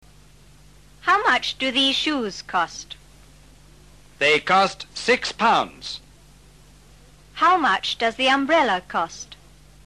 Ahora observa con atención las dos imágenes y escucha este audio en el cual aparece la nueva palabra HOW MUCH...? (¿CUÁNTO...?) que se pronuncia / jáu mách /.